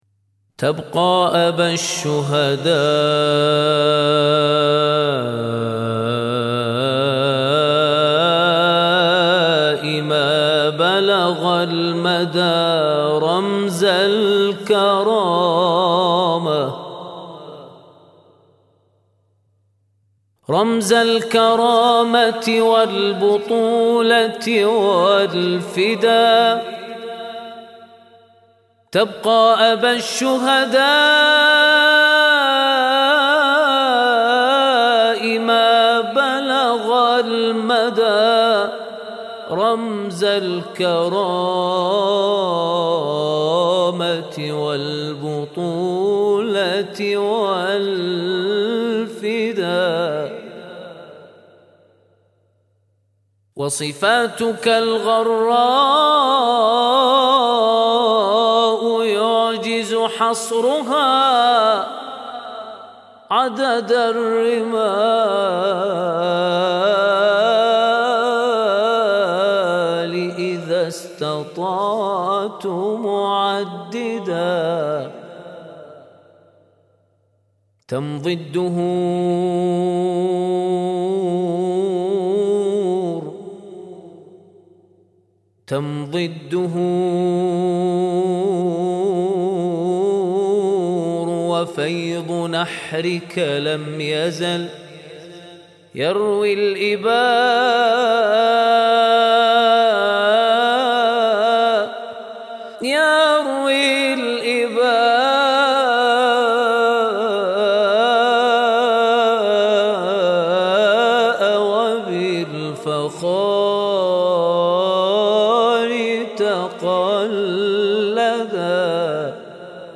مدائح بمناسبة ذكرى ولادة الإمام الحسين (ع)